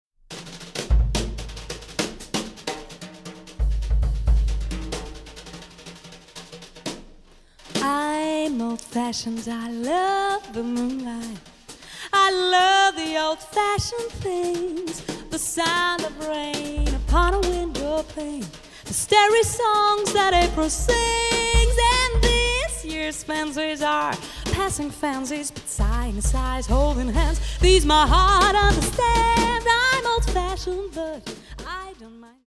Jazz Sängerin & Songwriterin